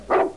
Barking Dog Sound Effect
barking-dog-1.mp3